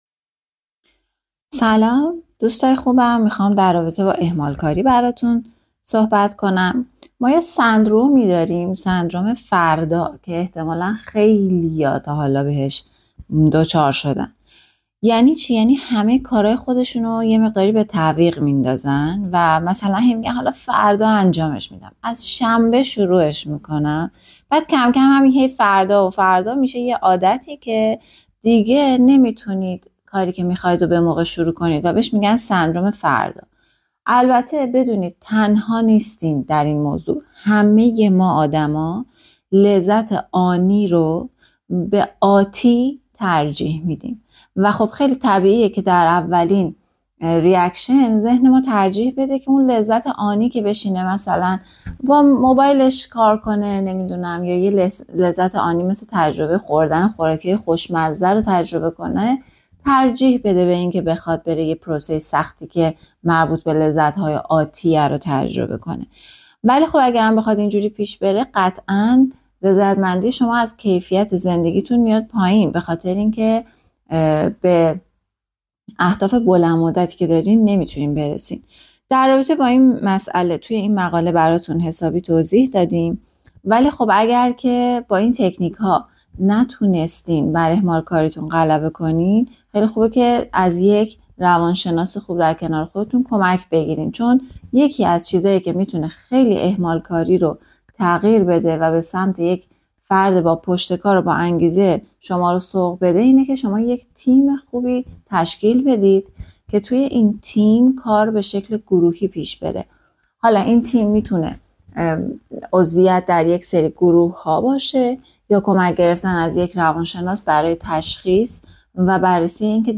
از زبان متخصص